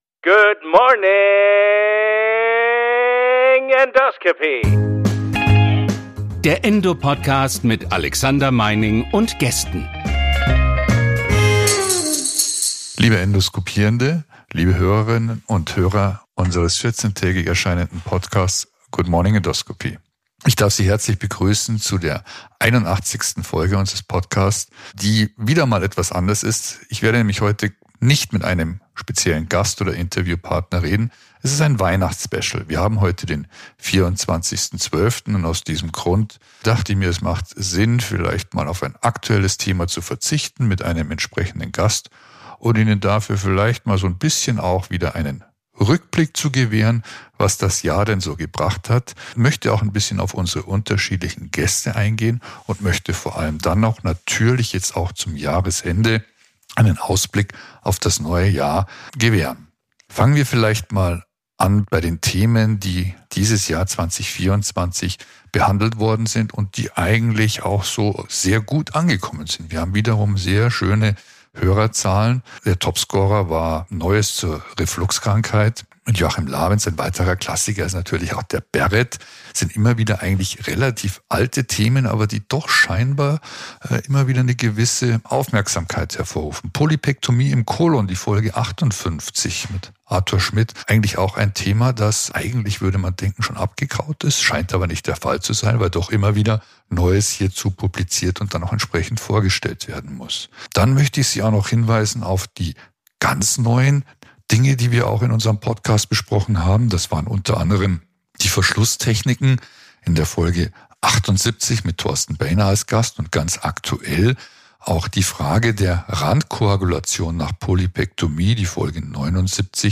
Feiertage/ Jahreswechsel: Zeit auf das vergangene Jahr zurückzublicken und sich für das neue Jahr vorzubereiten. Kein Gast, nur ein paar Fakten und auch Gedanken.